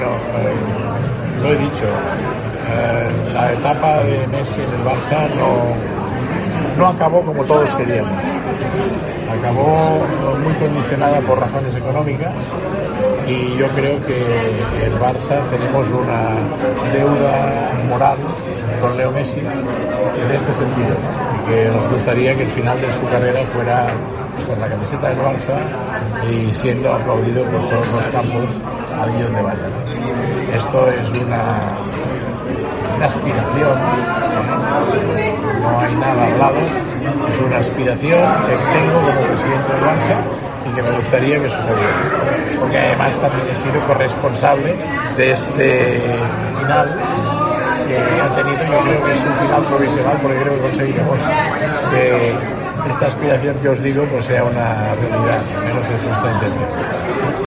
El presidente azulgrana, en un acto de la Fundación del Barça en la Quinta Avenida de Nueva York, reconoce que todavía no ha existido contacto alguno con el jugador.